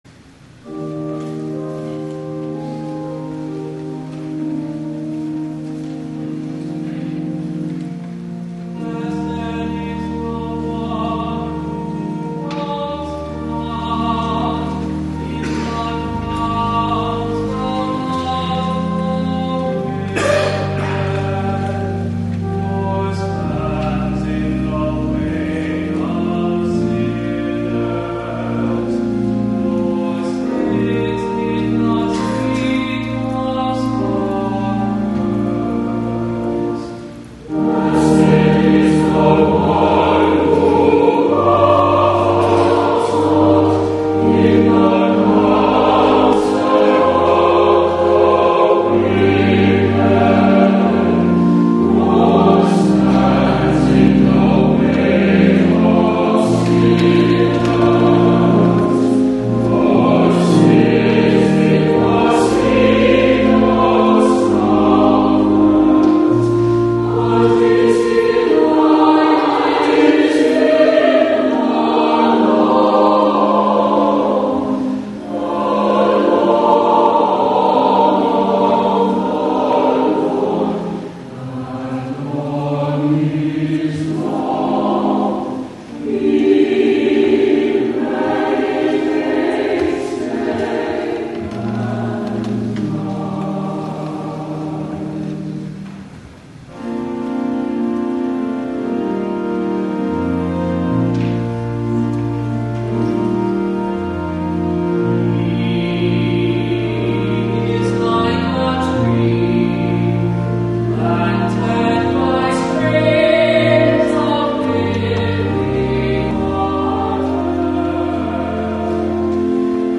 Morningside Presbyterian Church, Atlanta
THE ANTHEM